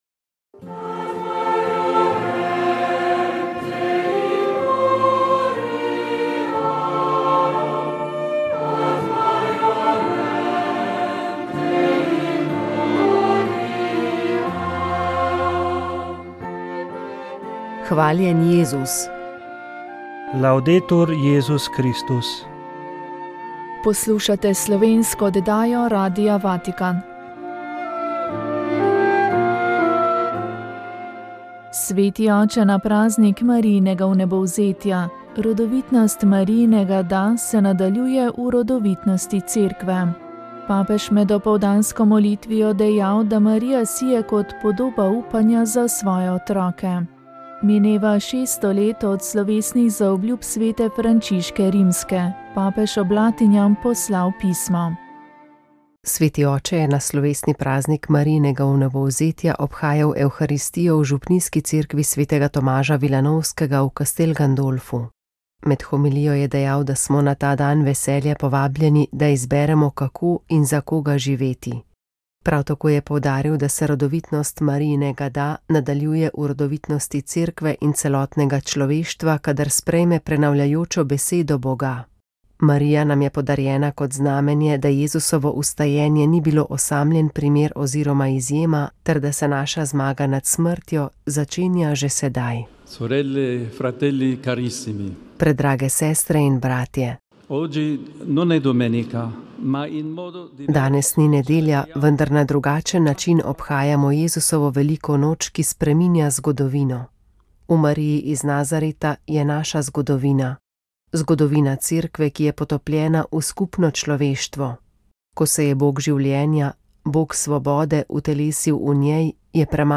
Tema pogovora pa bo med in njegovi zdravilni učinki. Med drugim bomo gostjo vprašali o izvoru medu in kako le tega ločimo od ponaredkov. Oddaja bo potekala v živo, zato boste lahko zastavljali tudi vprašanja.